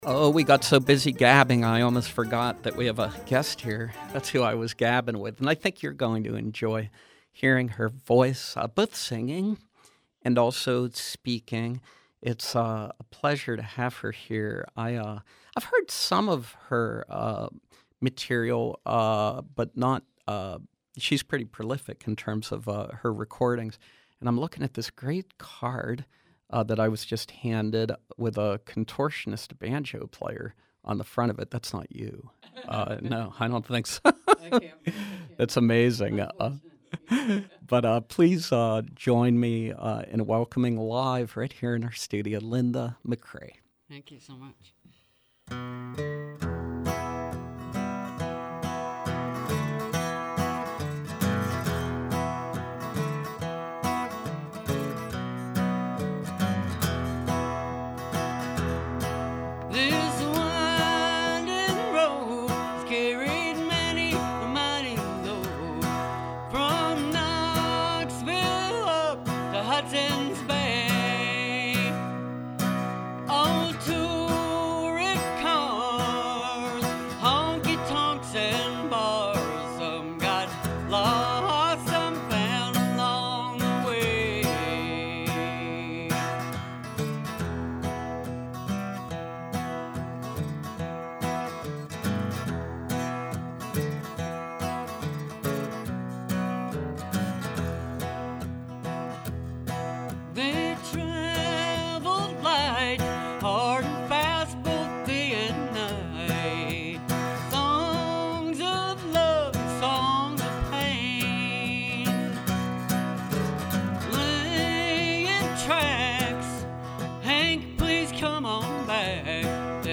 The talented singer-songwriter
joins us live in our studios.